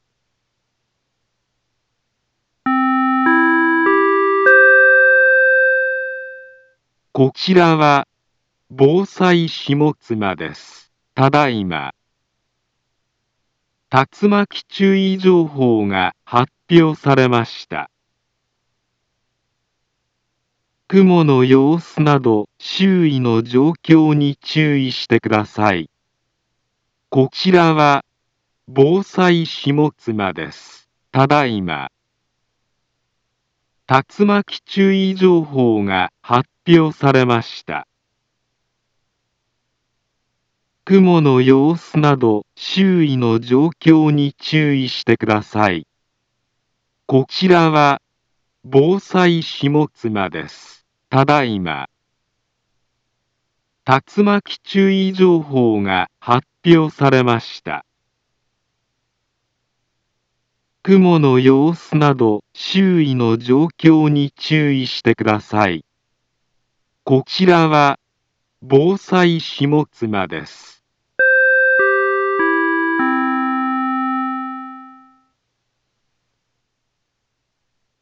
Back Home Ｊアラート情報 音声放送 再生 災害情報 カテゴリ：J-ALERT 登録日時：2023-09-20 17:29:51 インフォメーション：茨城県南部は、竜巻などの激しい突風が発生しやすい気象状況になっています。